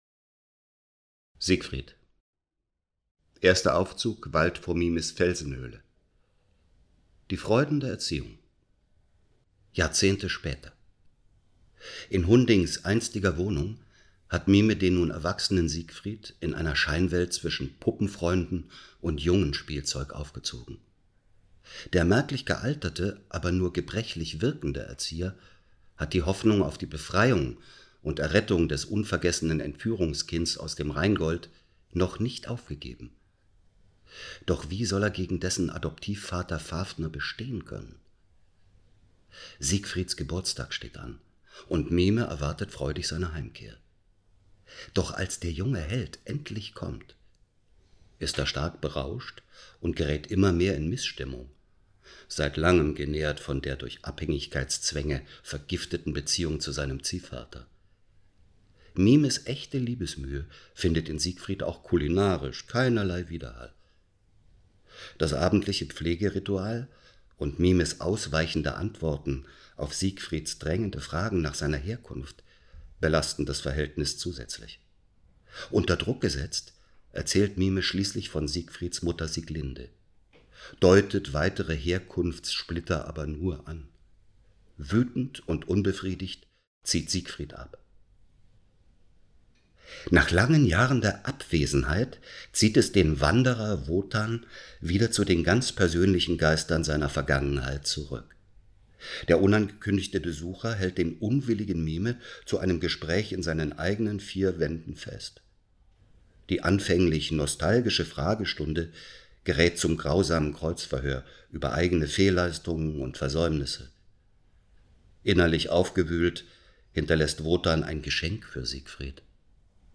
Sprecher: Sylvester Groth
Ringerzaehlung_3_Siegfried_DEU.mp3